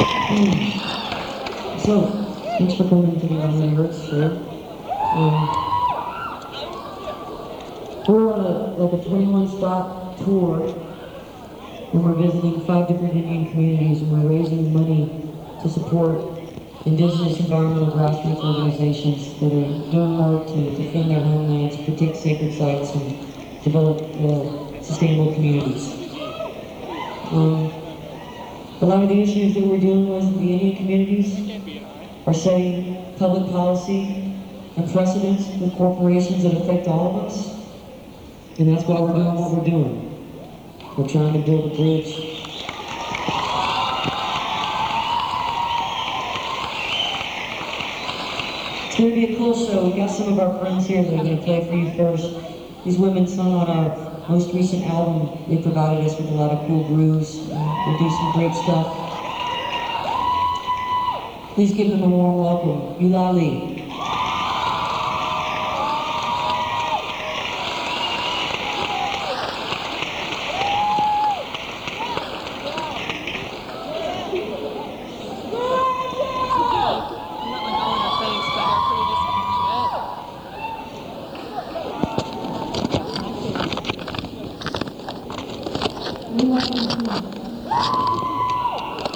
lifeblood: bootlegs: 1997-09-20: burruss auditorium - blacksburg, virginia
01. introduction by amy ray (1:29)